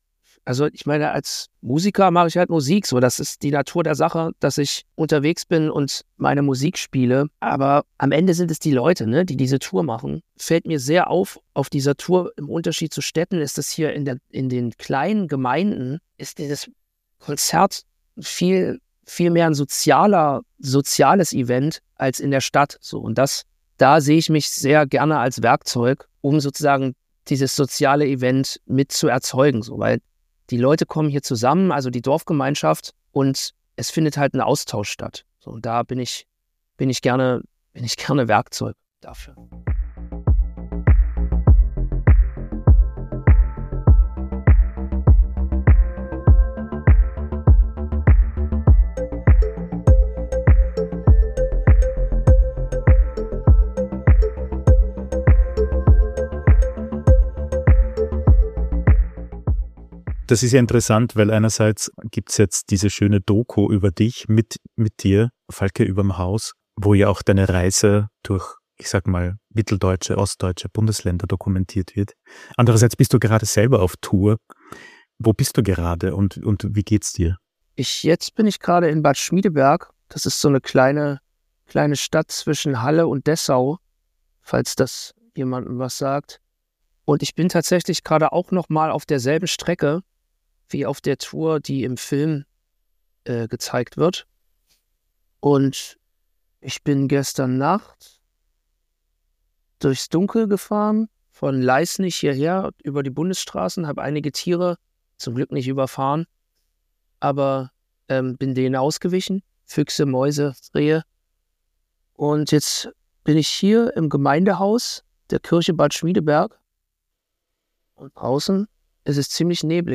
Ein Gespräch über Musik, Erinnerung, die Kraft der kleinen Orte und einen ganz eigenen Zugang zum Blues.